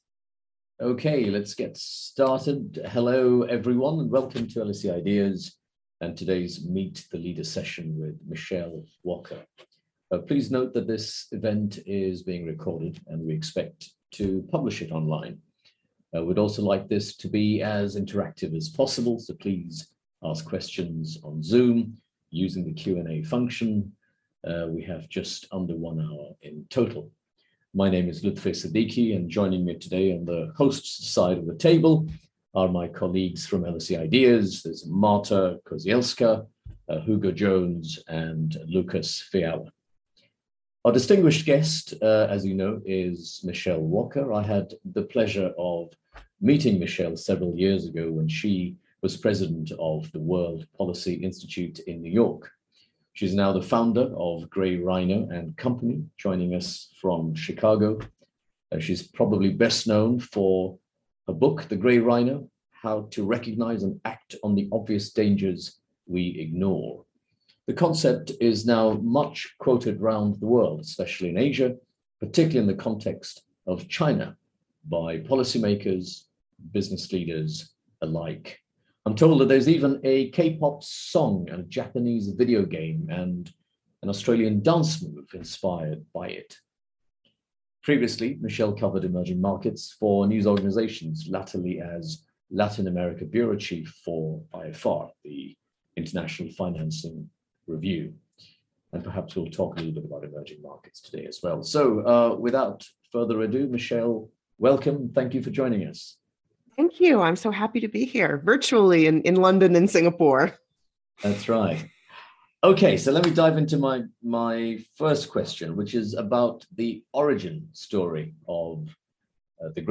Meet the speaker and chair